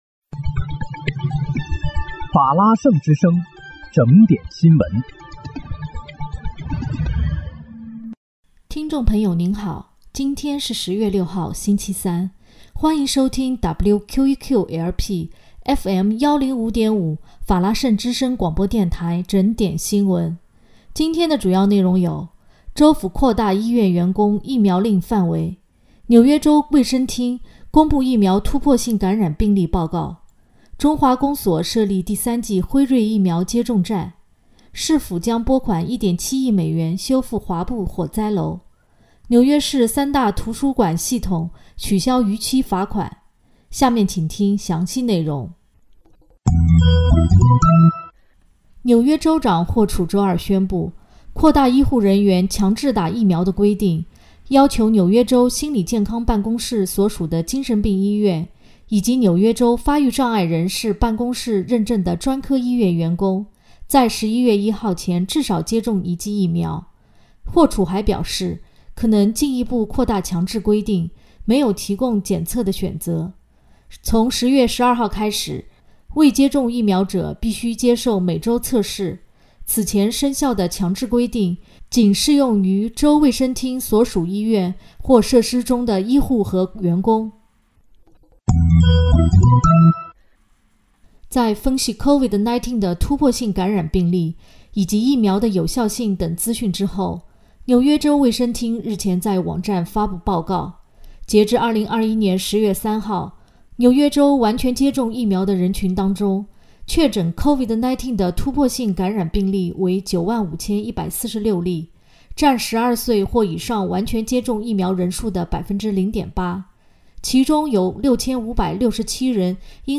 10月6日（星期三）纽约整点新闻